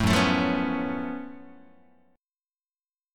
GbmM11 chord